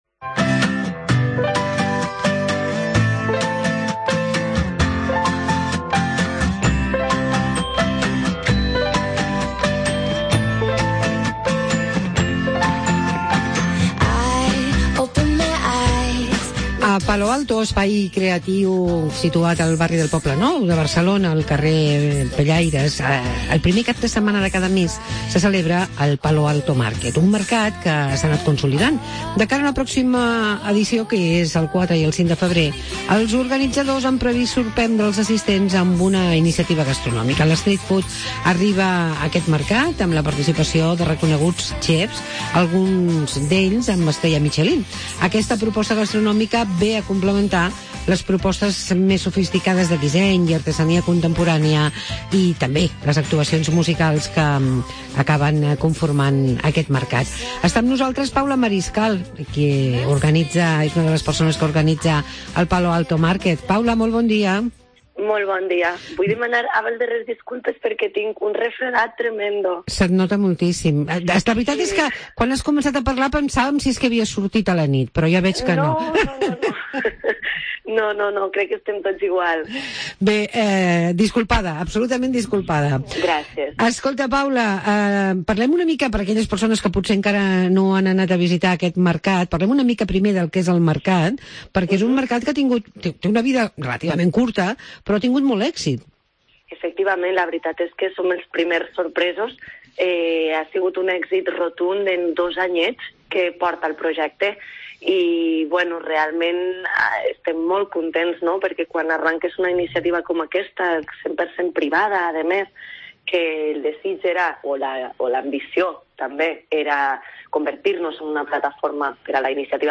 La propera setmana es celebra Palo Alto Market. Entrevista